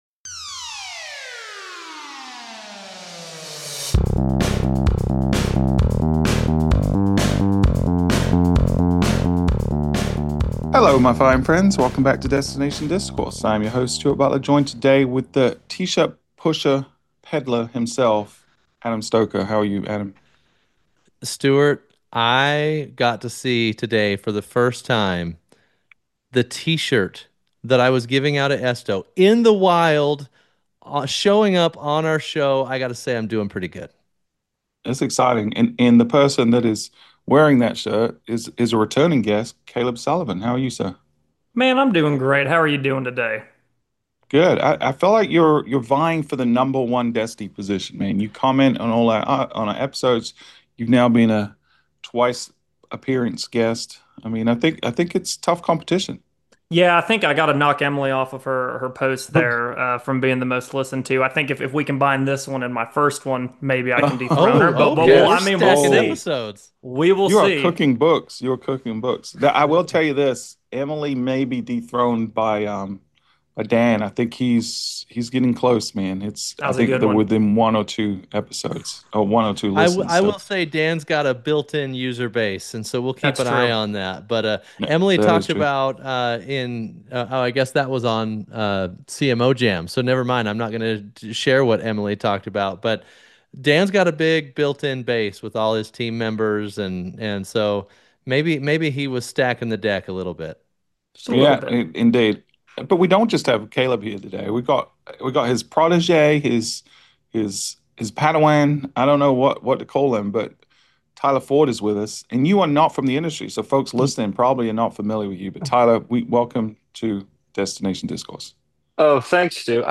The conversation kicks off with a lively debate about Las Vegas’s new “Five Days of Fabulous” campaign—is it a smart earned media play or a short-sighted brand devaluation?